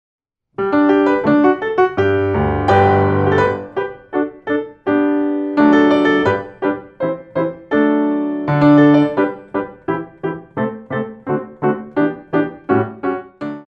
4 bar intro 2/4
64 bars